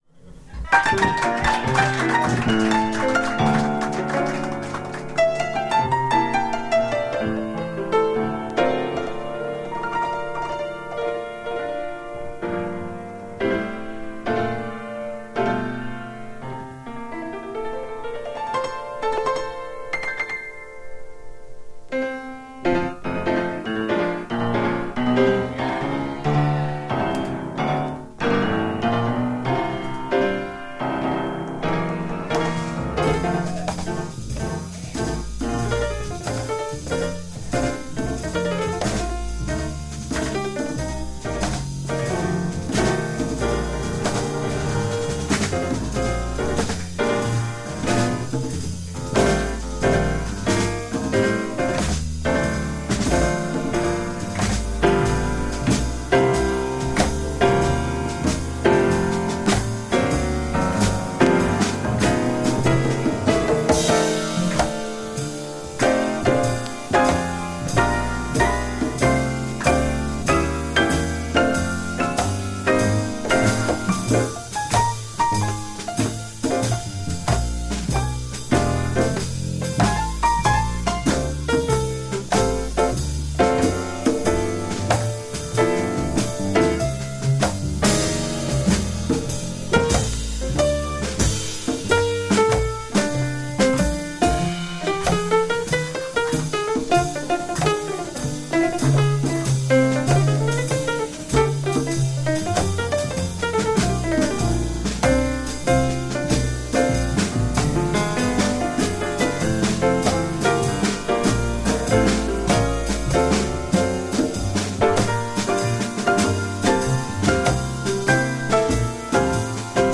ブルージーでスケールの大きい